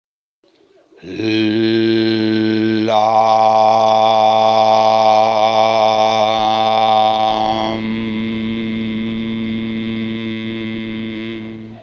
Il suono del mantra e sempre lento, basso e grave.